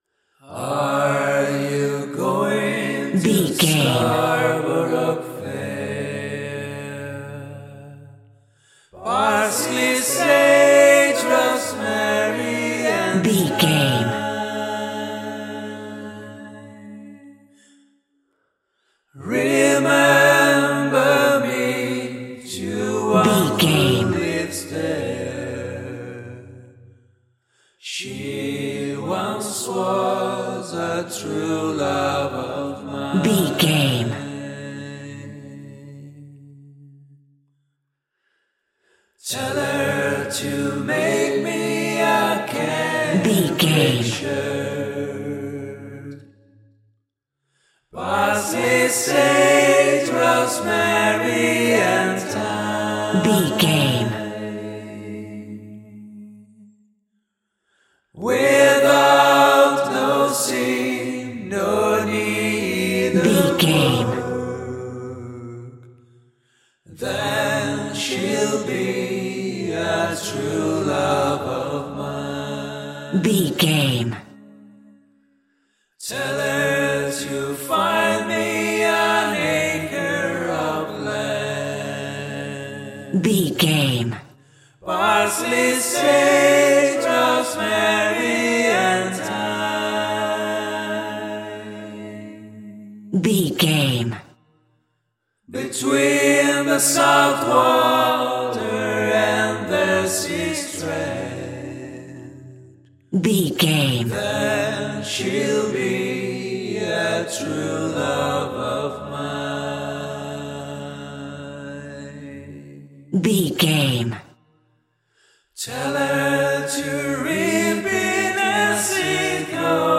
Aeolian/Minor
groovy
inspirational